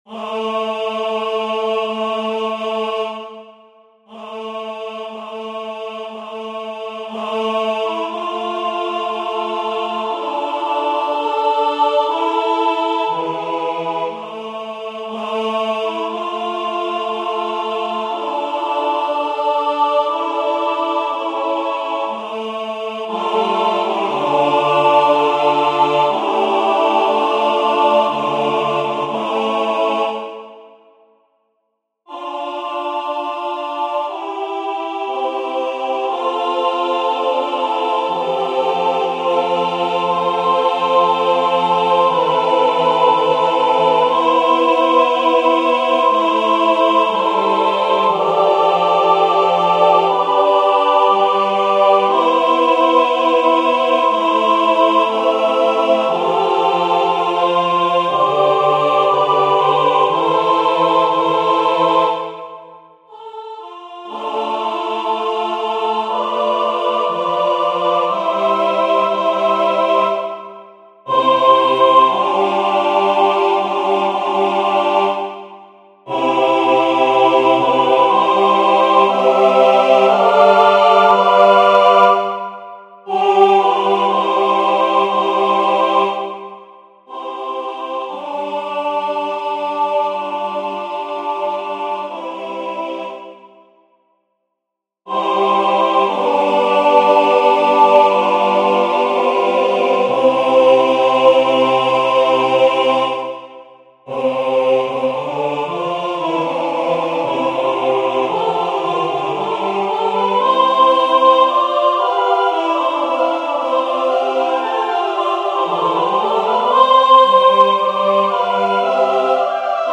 for a cappella choir
This a cappella choral work for soprano, mezzo-soprano, alto and baritone is set to an original Latin text and expresses my deep longing for inner peace. In a spirit of prayer, the music asks God for mercy and forgiveness — first in my own name, then in the name of all of us, and finally in the name of all creatures on Earth.
Its contemplative atmosphere and devotional character evoke the tradition of Catholic sacred choral music, while remaining a personal and spiritual meditation on grace, peace, and reconciliation.